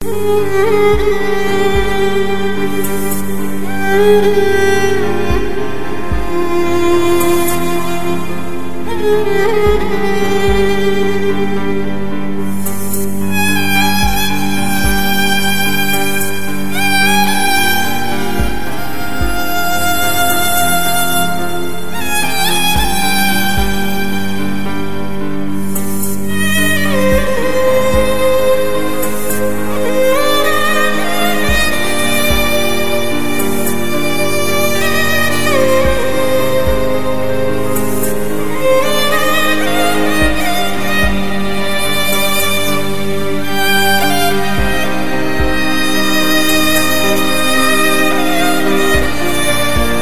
Sad violin